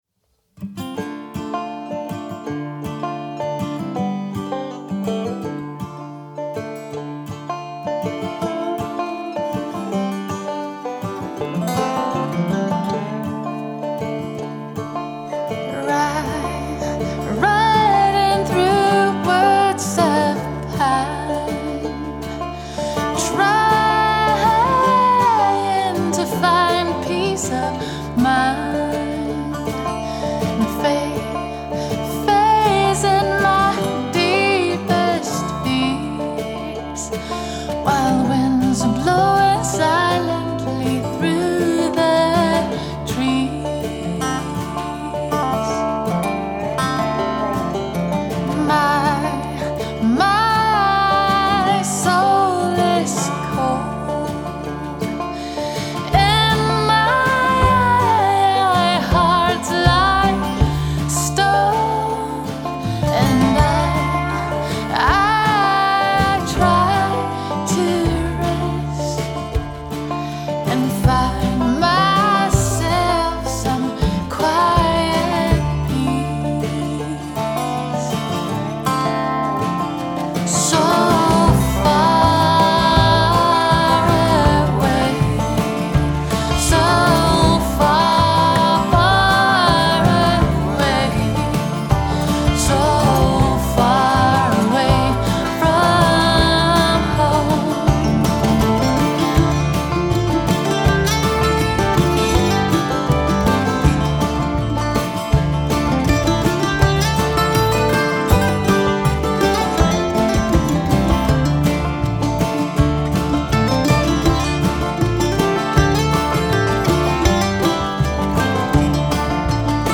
Guitar, Upright Bas, Mandolin, Violin, and Dobro.